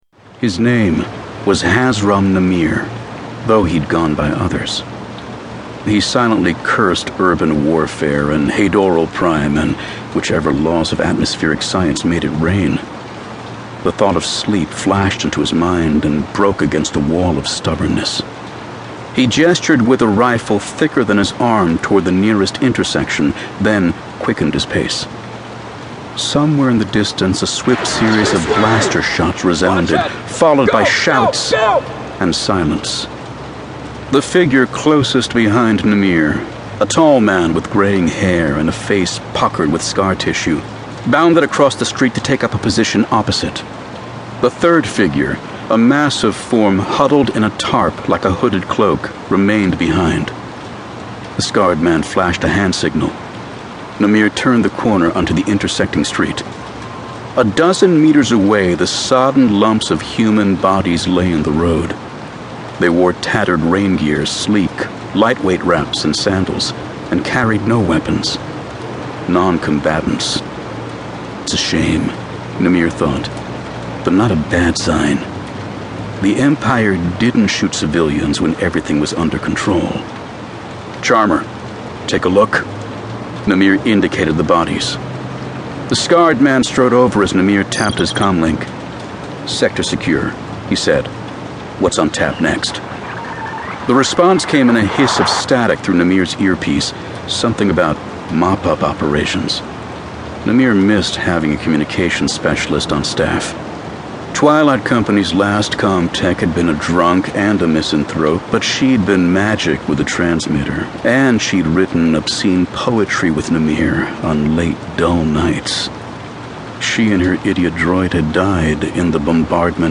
Star Wars Battlefront: Twilight Company Audio Book Excerpt